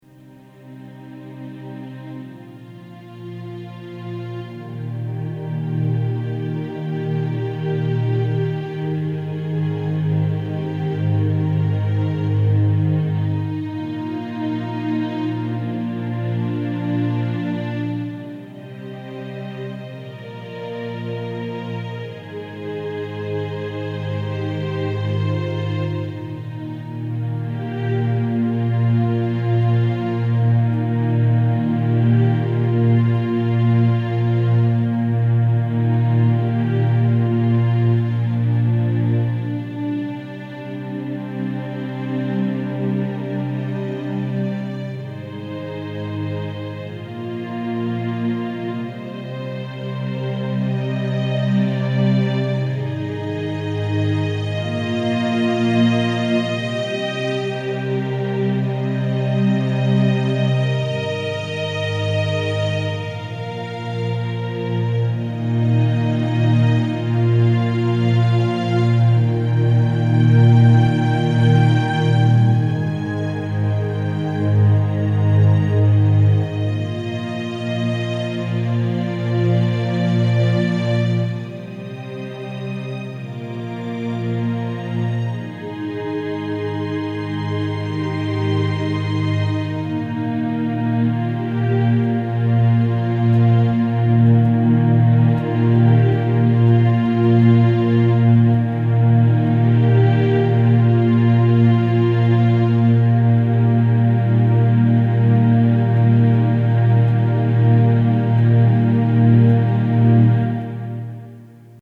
i learned the theme, in fact...